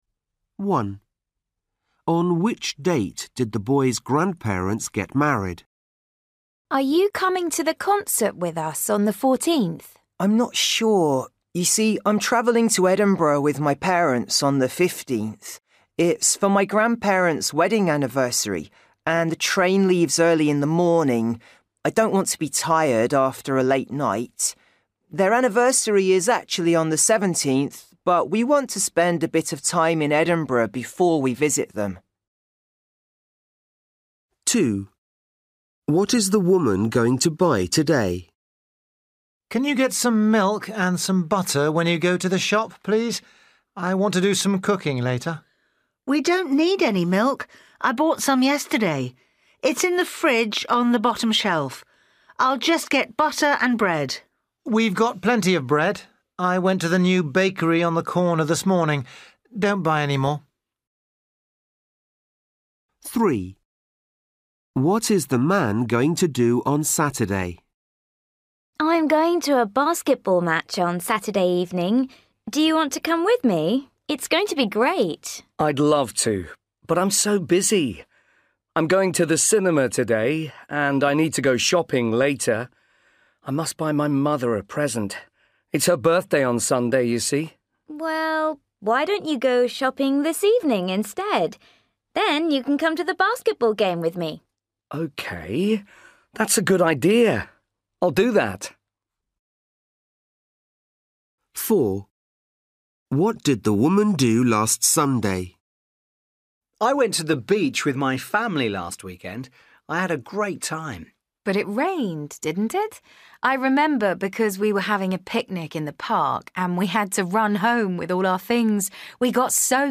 Practice PET (B1) Listening Test 15 with Answers and Audioscripts